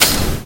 噗通/噗通
描述：消失或消失的噗噗声。
Tag: 空气 消失 消失 粉扑